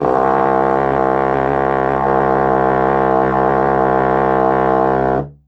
Index of /90_sSampleCDs/Best Service ProSamples vol.52 - World Instruments 2 [AKAI] 1CD/Partition C/TENOR HORN